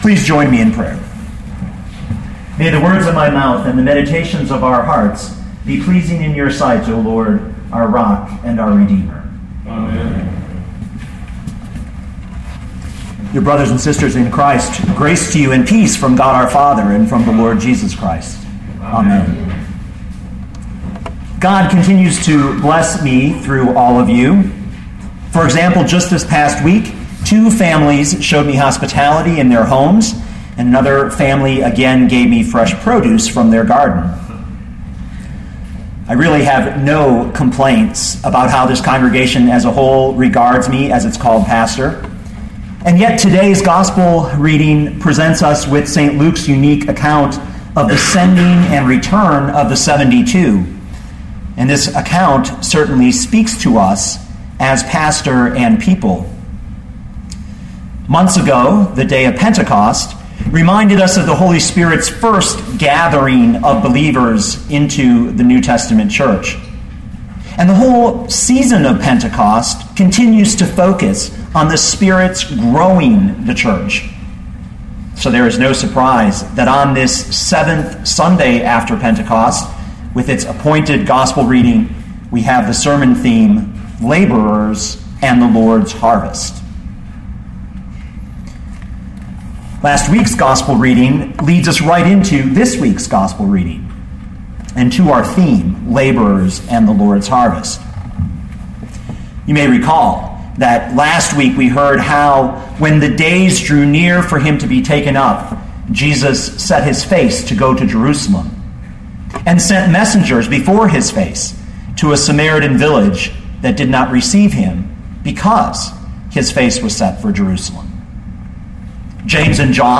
Pilgrim Lutheran Church — Laborers and the Lord’s Harvest